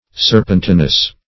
Search Result for " serpentinous" : The Collaborative International Dictionary of English v.0.48: Serpentinous \Ser"pen*ti`nous\, a. Relating to, or like, serpentine; as, a rock serpentinous in character.